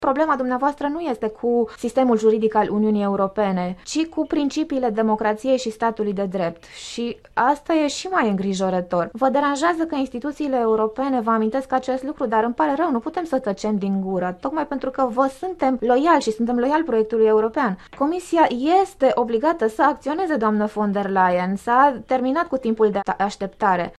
Și socialiștii europeni l-au condamnat dur pe premierul Poloniei, în plenul Parlamentului Uniunii, prin vocea liderului de grup, Iratxe Garcia Perez: